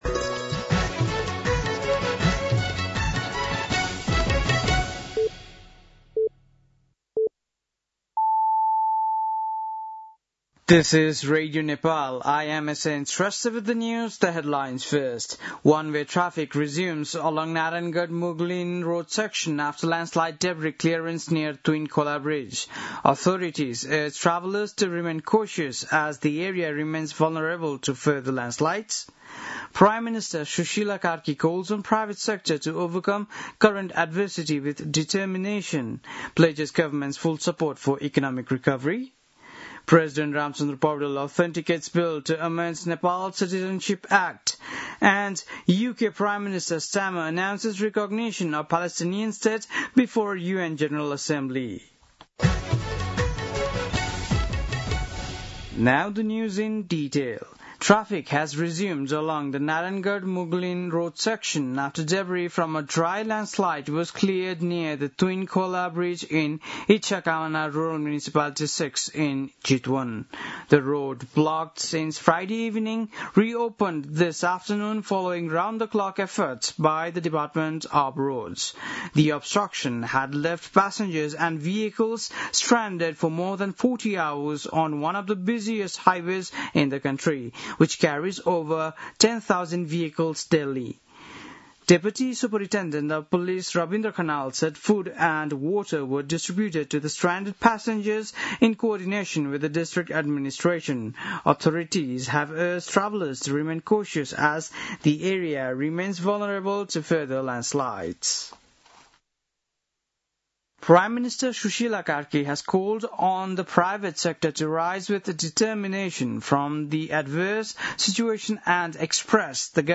बेलुकी ८ बजेको अङ्ग्रेजी समाचार : ५ असोज , २०८२
8.-pm-english-news-1-1.mp3